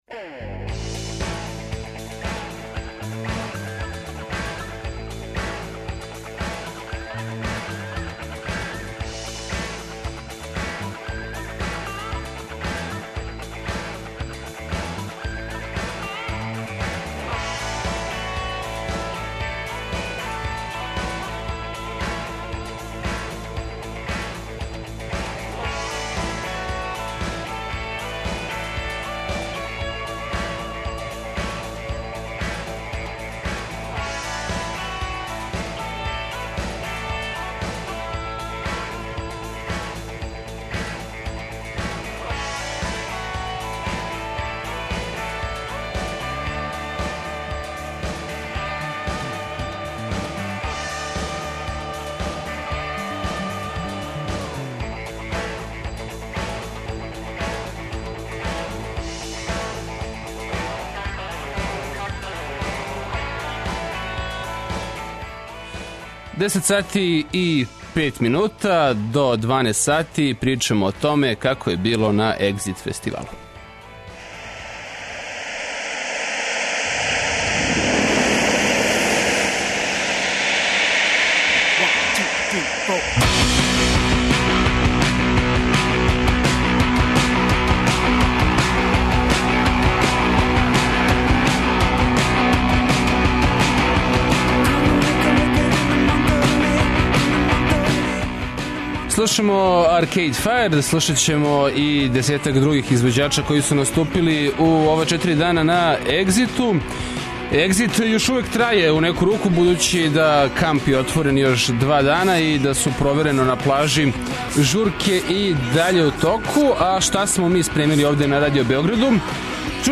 Саговорници су музичари и колеге новинари који су пратили фестивал, као и публика фестивала.